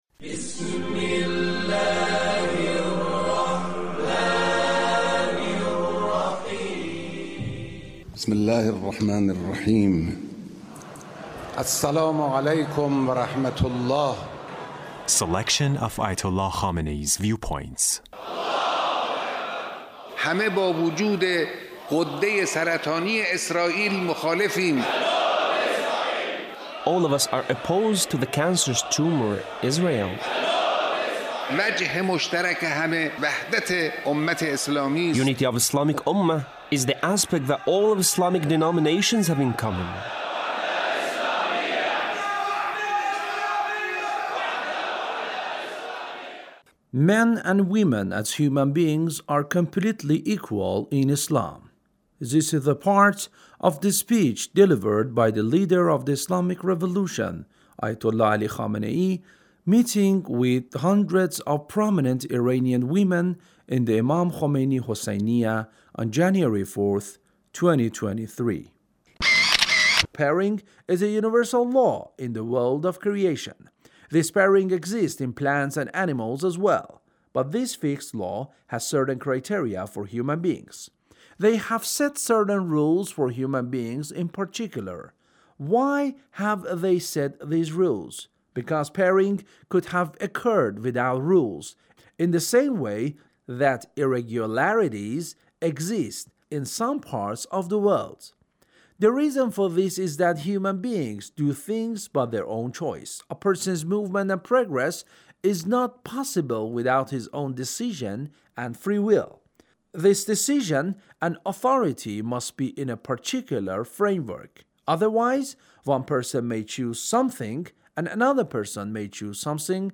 Leader's Speech (1613)
Leader's Speech meeting with hundreds of prominent Iranian women